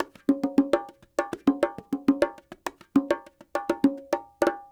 44 Bongo 09.wav